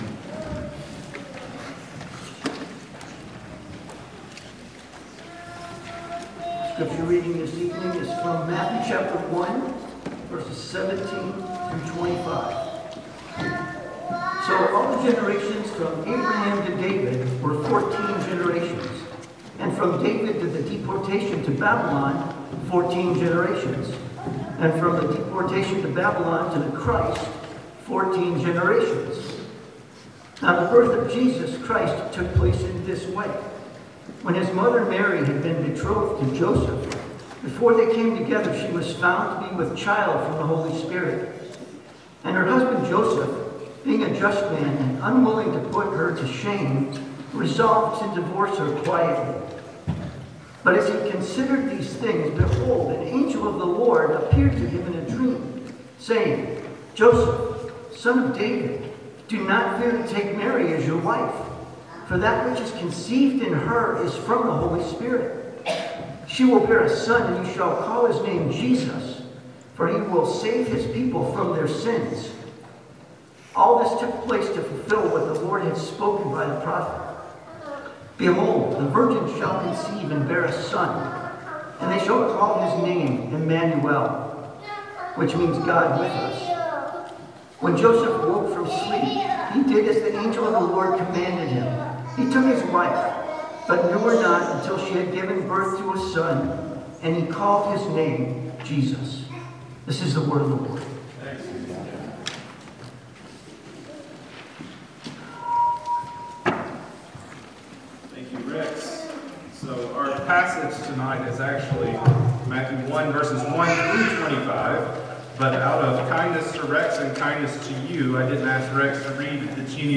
Passage: Matthew 1:1-25 Sermon